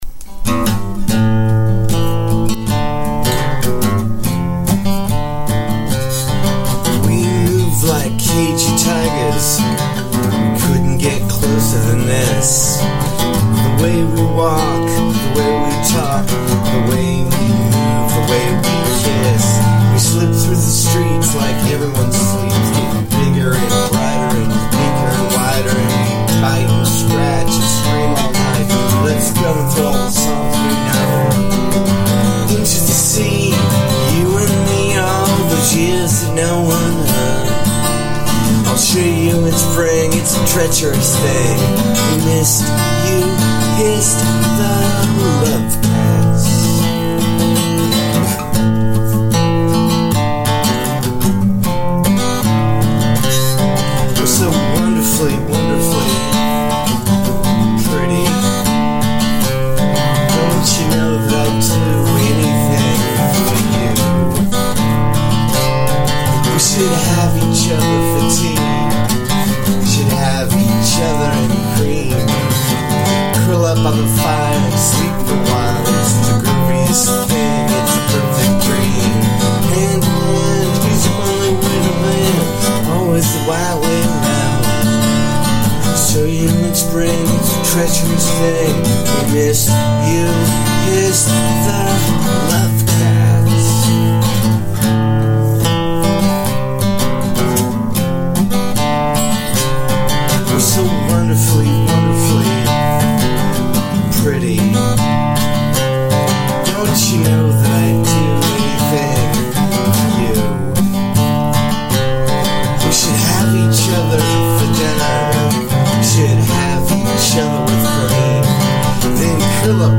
cover
Singing that low makes the song unhappy, and moody.